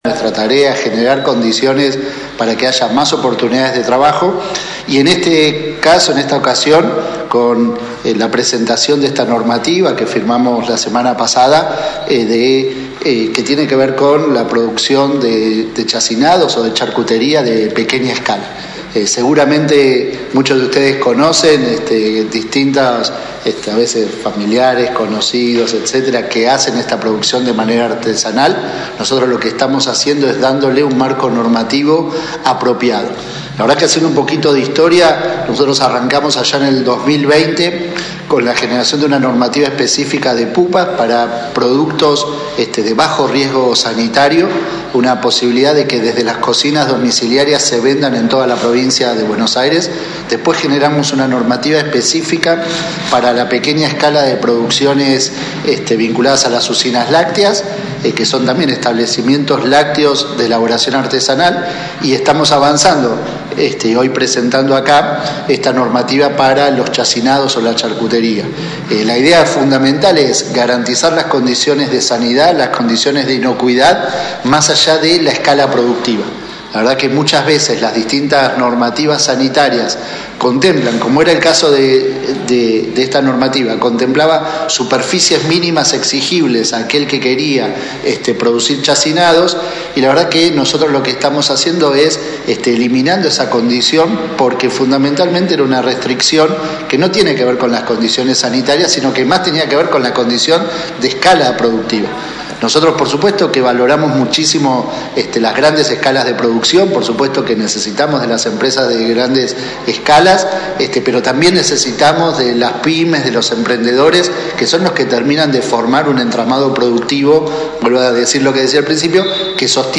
Previamente, el funcionario, acompañando por el intendente Alberto Gelené, recibió a la prensa local en el salón de audiencias.